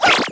girl_toss_chair.wav